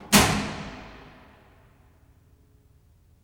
Heavy Switch (3).wav